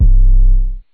GOOD AM 808.wav